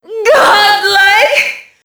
Index of /server/sound/foot/quake/female